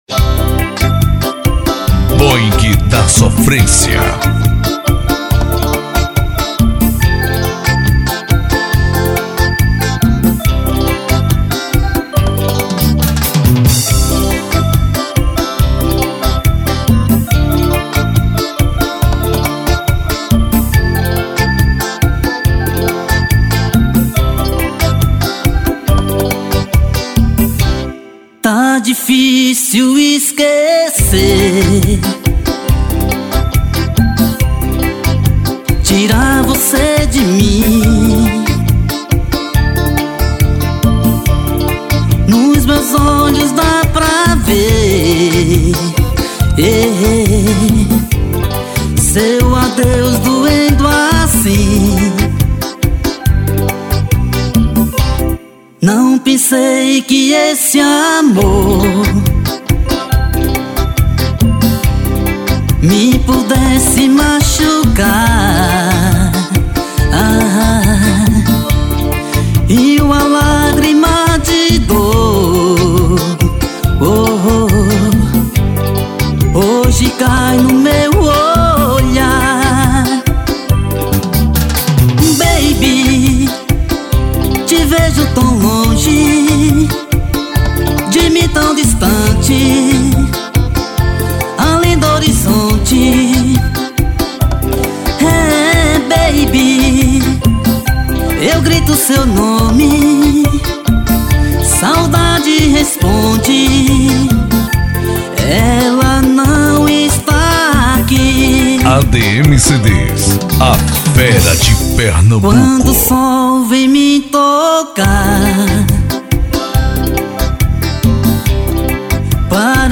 Ao Vivo.